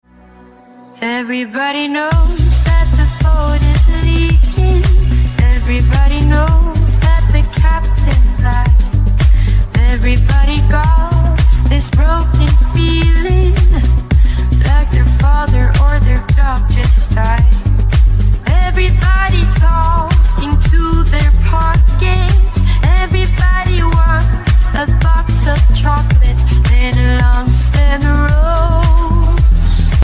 • Качество: 128, Stereo
deep house